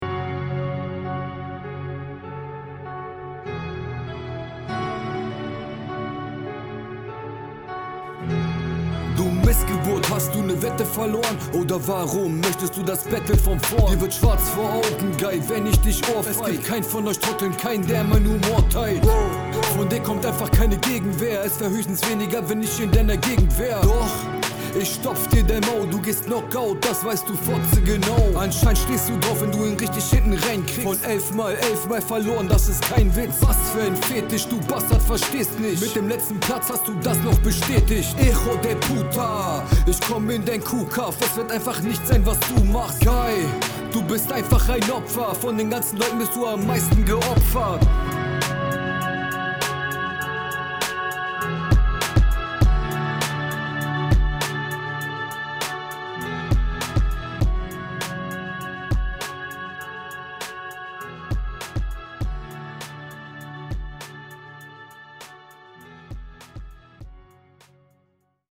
starke Hinrunde hier, hätte mir mehr Flowvarriationen gewünscht, aber insgesamt schöne Runde